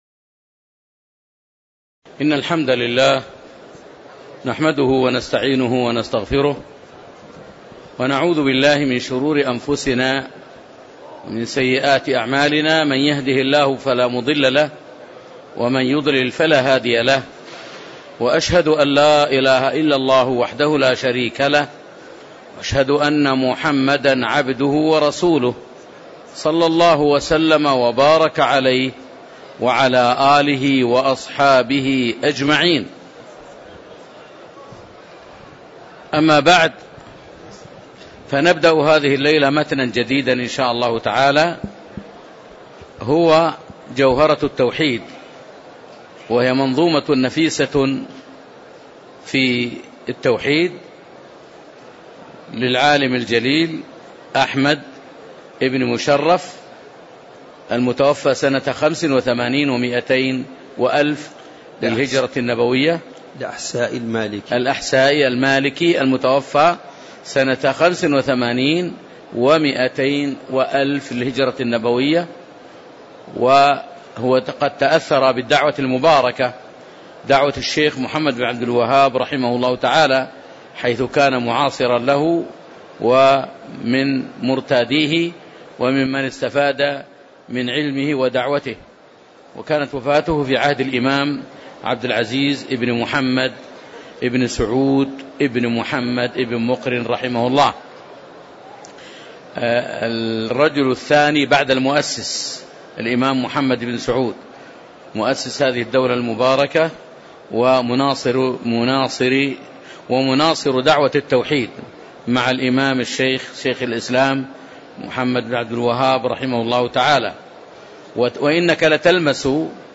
تاريخ النشر ٢٦ جمادى الآخرة ١٤٣٧ المكان: المسجد النبوي الشيخ